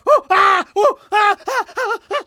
fire_scream1.ogg